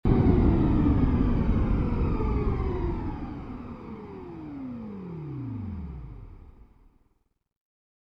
enginestop.wav